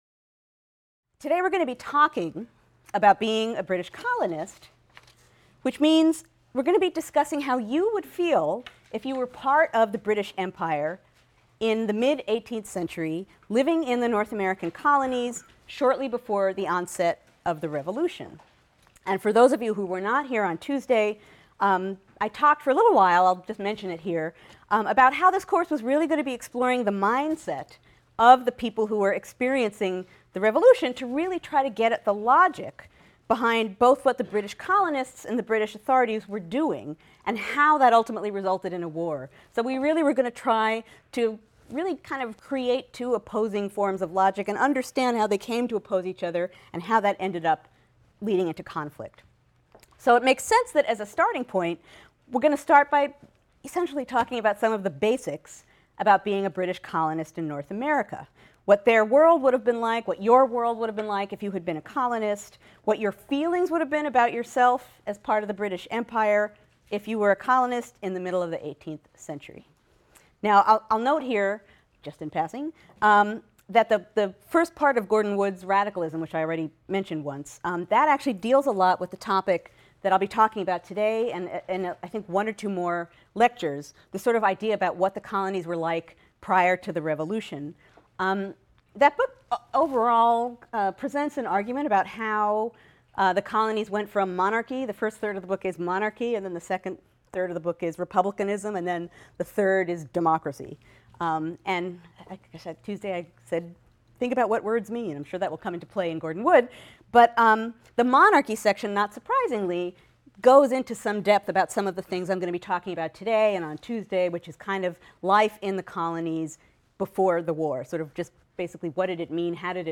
HIST 116 - Lecture 2 - Being a British Colonist | Open Yale Courses